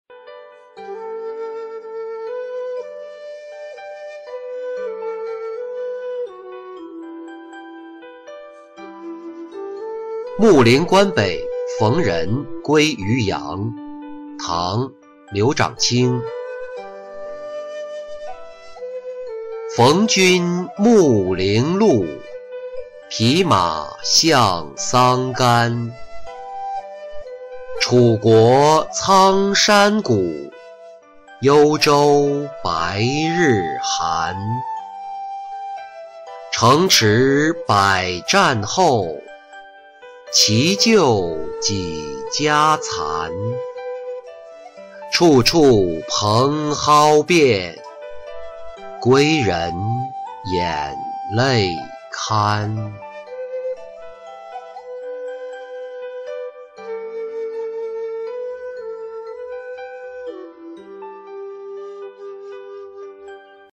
穆陵关北逢人归渔阳-音频朗读